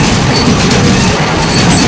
rr3-assets/files/.depot/audio/sfx/car_damage/scrape_wall.wav
scrape_wall.wav